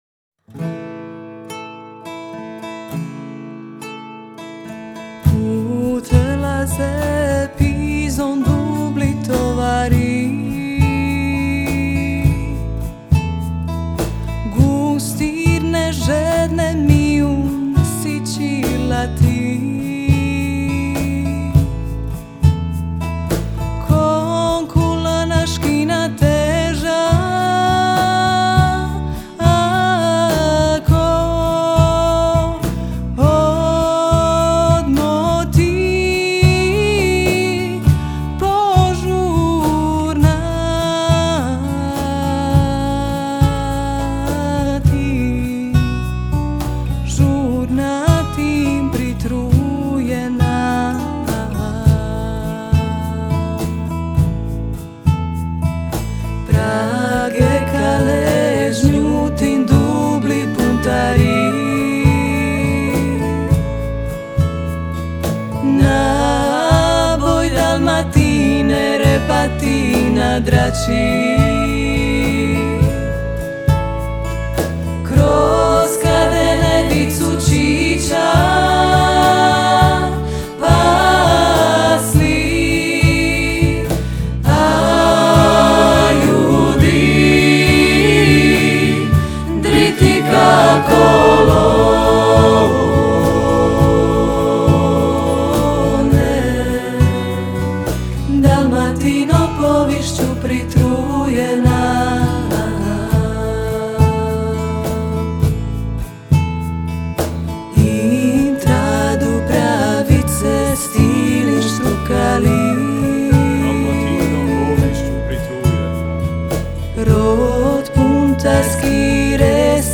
vokalno-instrumentalni ansambl
studentske obrade hrvatske tradicijske glazbene baštine
gitara, vokal
saksofon, vokal
gitara, udaraljke, flauta, vokal
bas gitara
violina, vokal